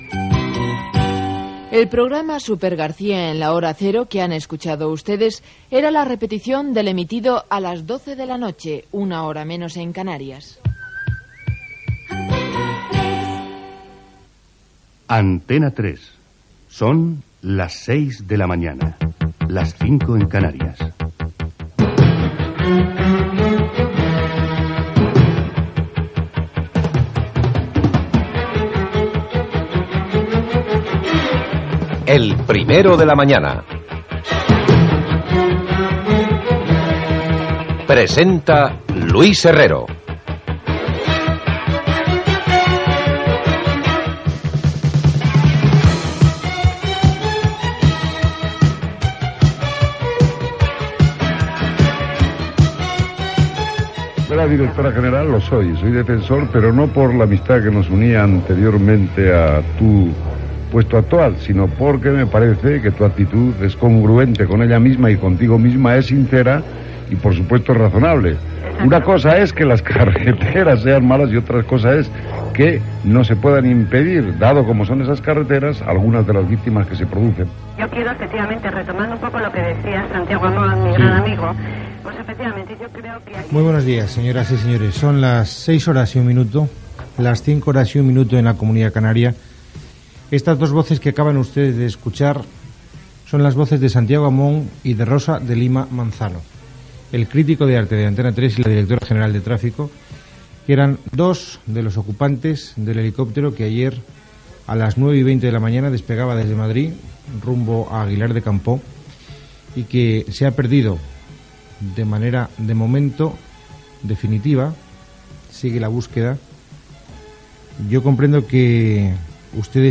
Careta del programa, hora, informació de l' accident de l'helicòpter en el qual viatjàven el col·laborador d'Antena 3 Santiago Amón i la directora general de la Dirección General de Tráfico, Rosa de Lima Manzano. Connexió amb Navacerrada, a la serra de Madrid, per informar de l'accident.
Gènere radiofònic Informatiu